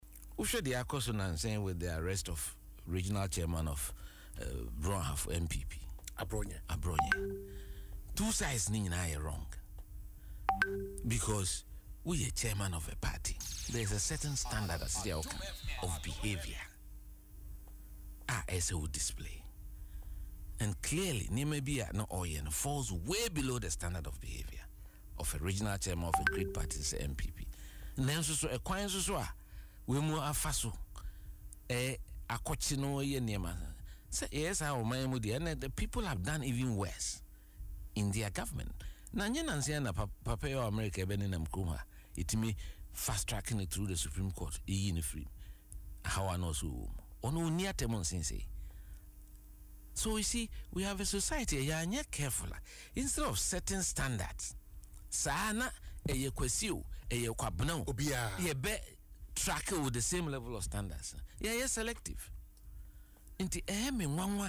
Speaking in an interview on Adom FM’s Dwaso Nsem, Mr. Agyepong said while Abronye’s behaviour fell short of the standards expected of a regional chairman, the manner in which law enforcement responded also raised serious questions.